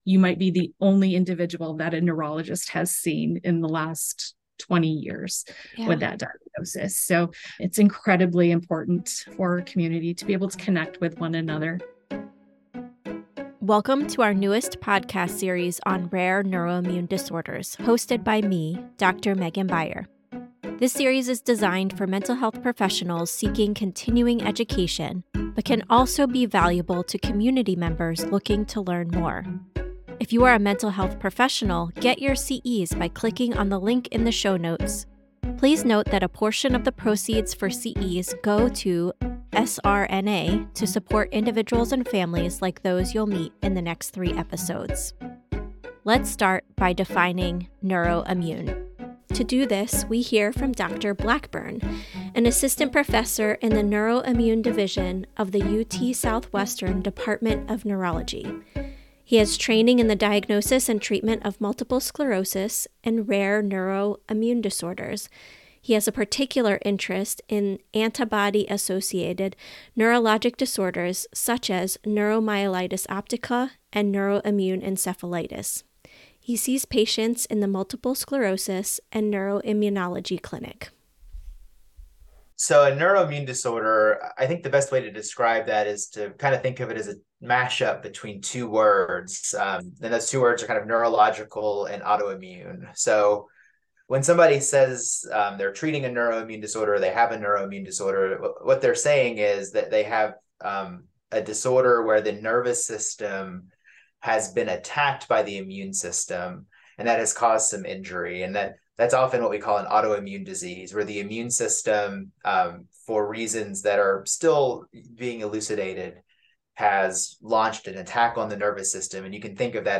We teach you how to care for your patients' mental health amid serious or complex medical problems. Listen to engaging interviews with patients, internationally renowned researchers, and skilled clinicians.